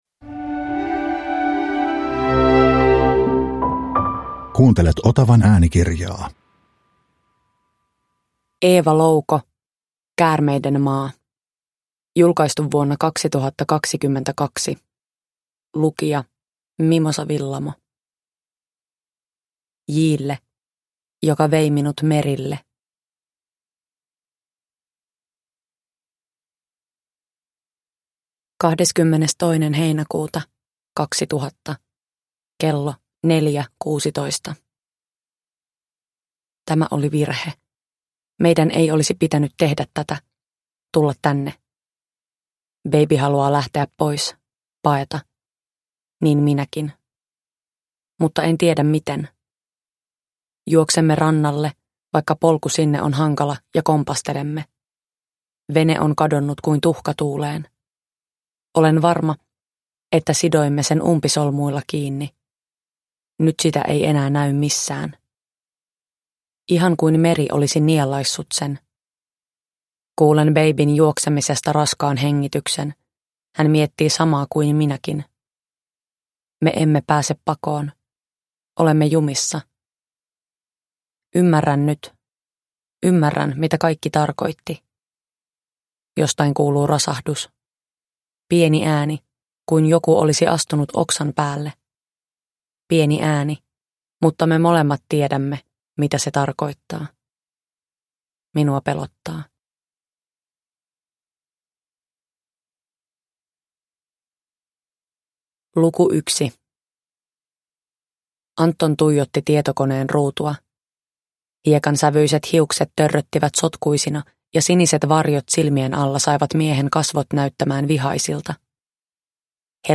Käärmeiden maa – Ljudbok – Laddas ner
Uppläsare: Mimosa Willamo